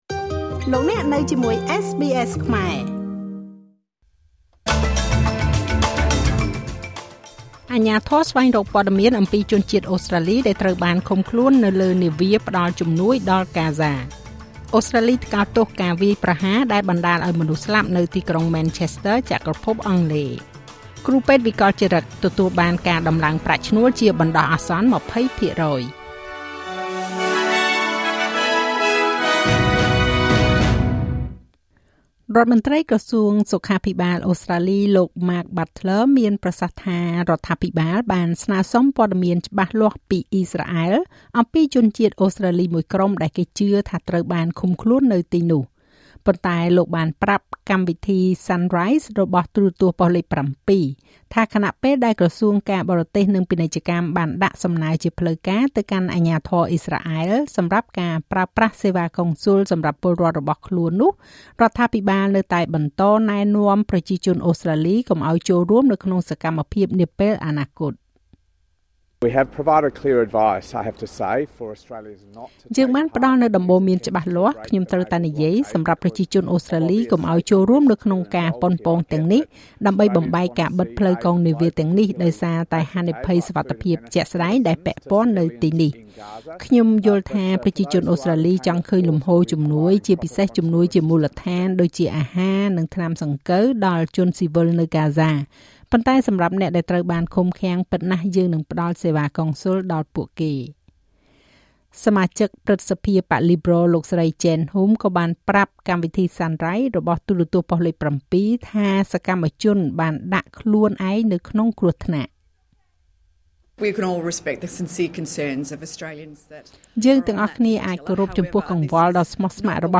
នាទីព័ត៌មានរបស់SBSខ្មែរ សម្រាប់ថ្ងៃសុក្រ ទី៣ ខែតុលា ឆ្នាំ២០២៥